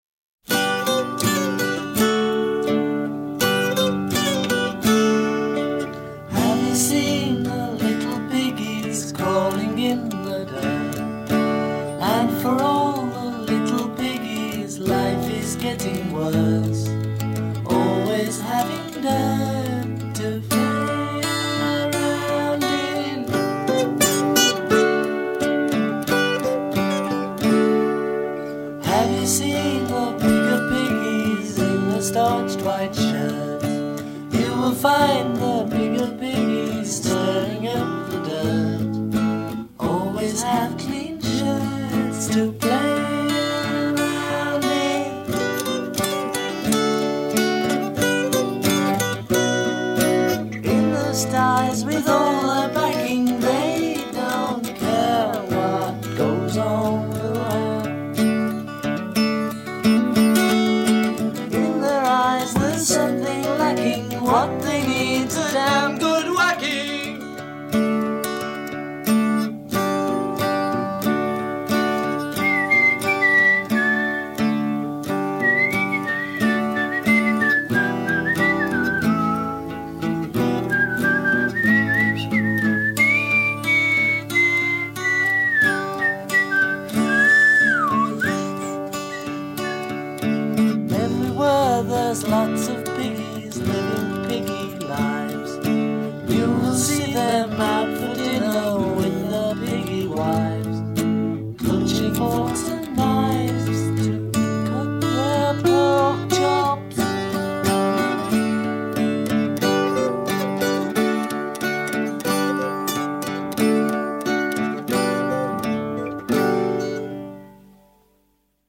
Рок музыка